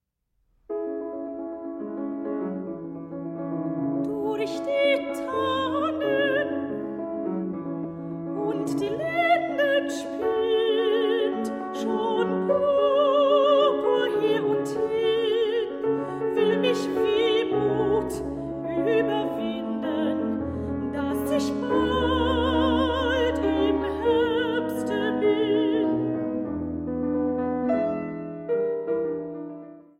Sopran
Klavier